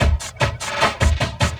45 LOOP 01-R.wav